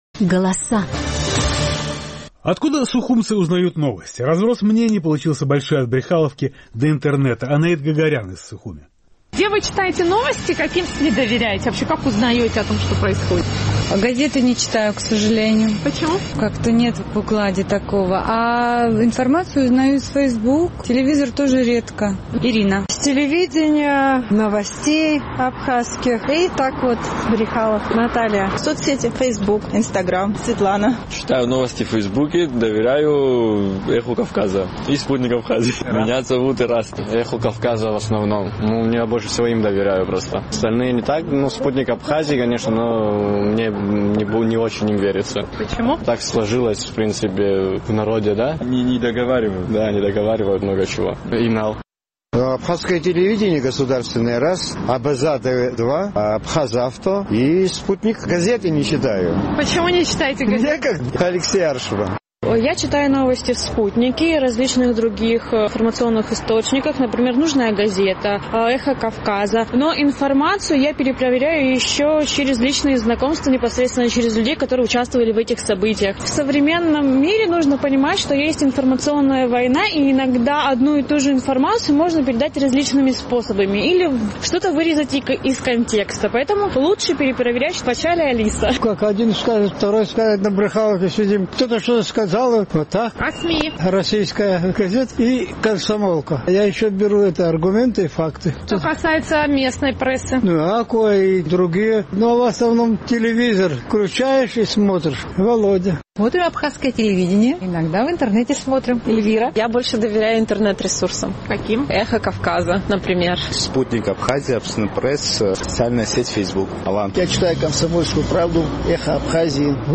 Наш сухумский корреспондент поинтересовалась, откуда местные жители узнают новости. Разброс получился большой: от Брехаловки до интернета.